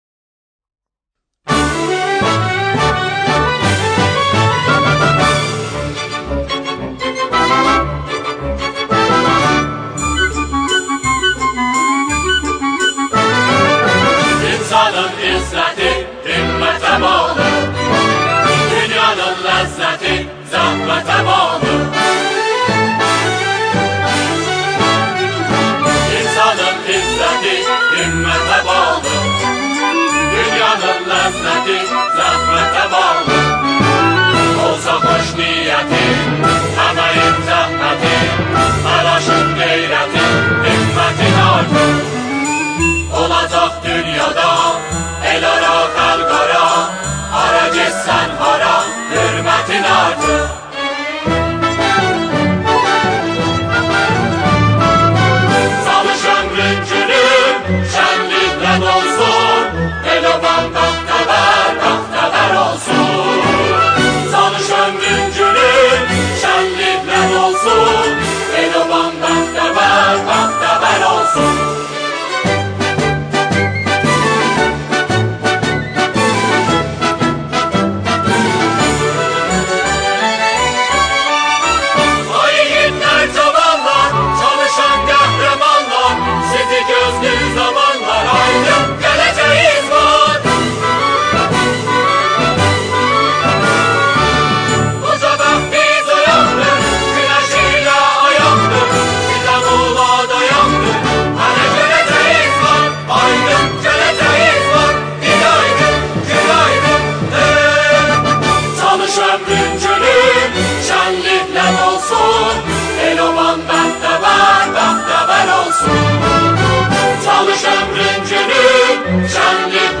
با اجرای گروهی از همخوانان